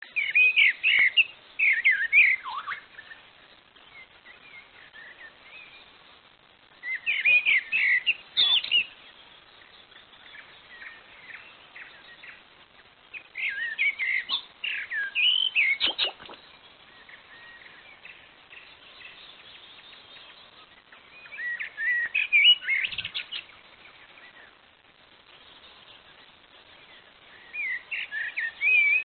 آواز و صدای پرندگان